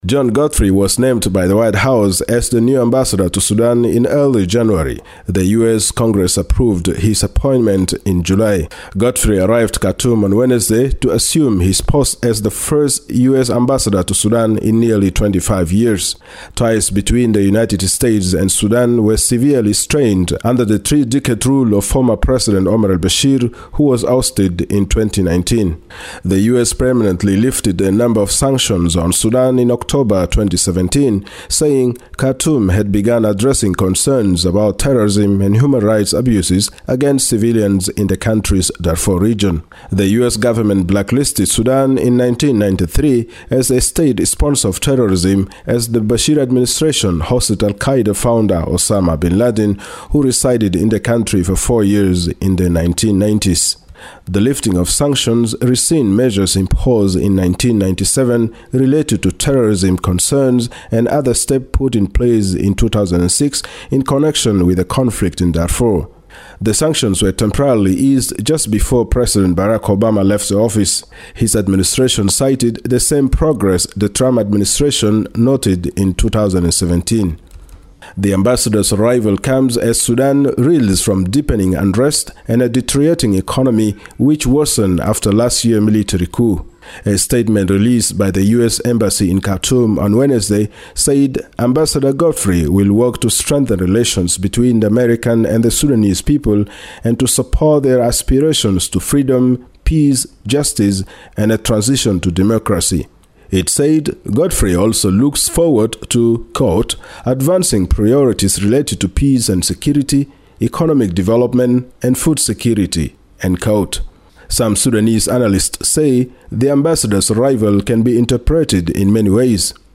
reports from Khartoum